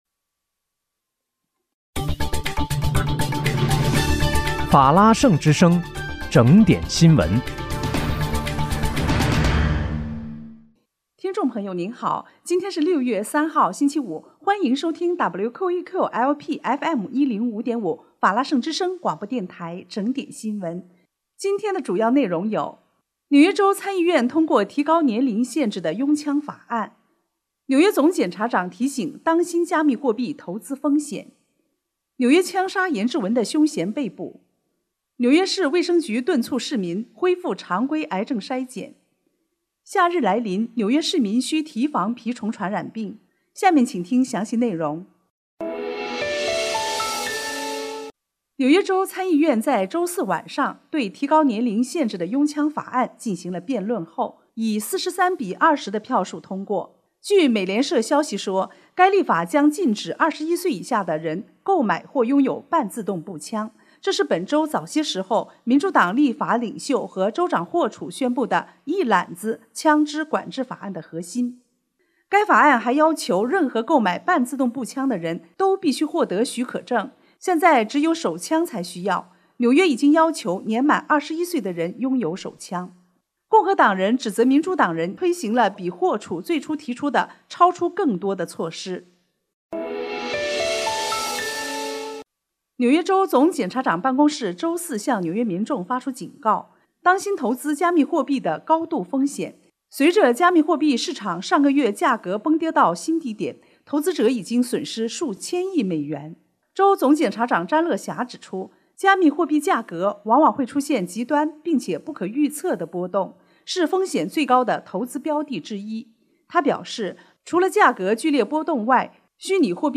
6月3日（星期五）纽约整点新闻